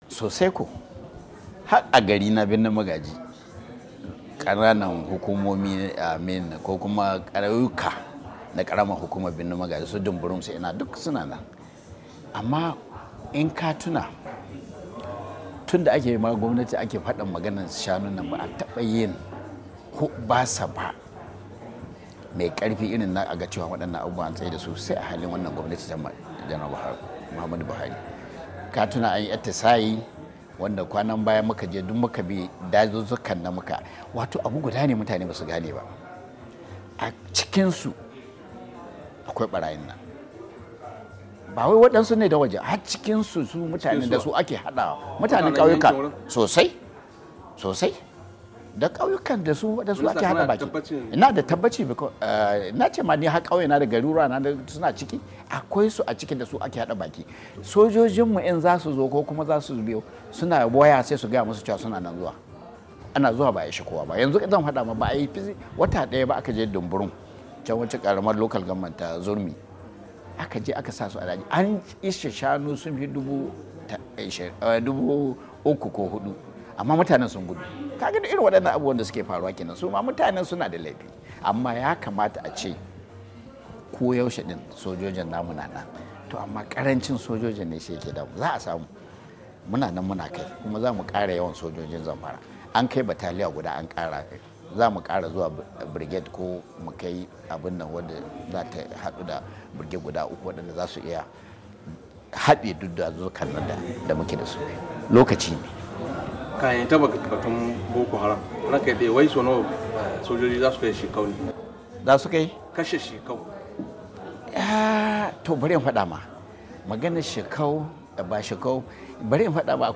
Cikin firar da yayi da Muryar Amurka a taron Majalisar Dinkin Duniya ministan tsaron Najeriya Janar Mansur Dan Ali yace wasu mutane dake zaune a kauyuka suna hada baki da barayi a arewacin kasar suna satar shanu ko mutane.